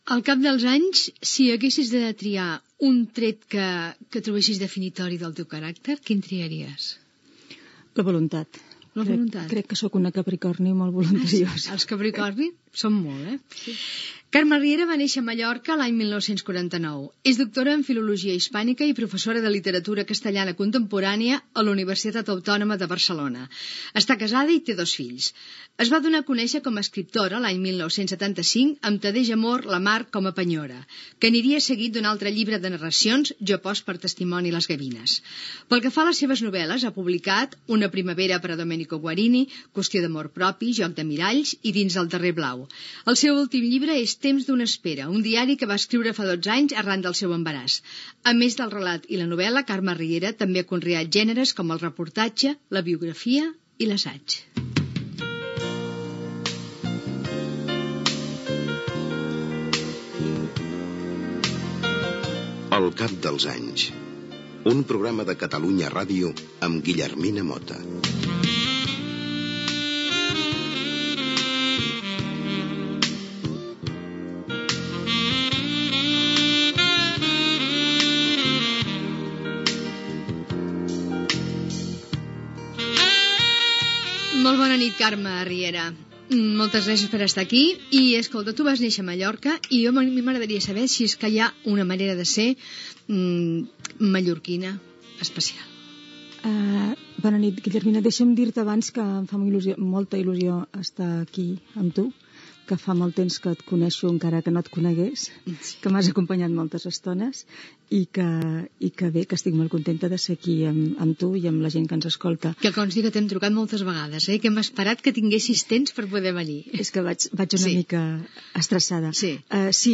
Perfil biogràfic de l'escriptora mallorquina Carme Riera, careta del programa, entrevista a l'escriptora sobre Mallorca, el mar, etc.